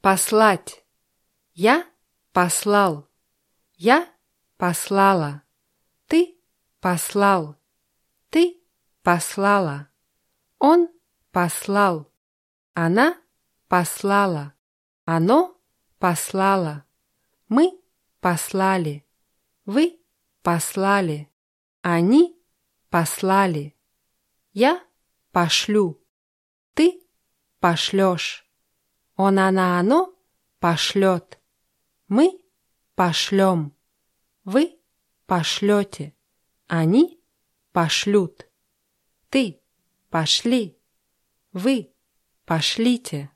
послать [paslátʲ]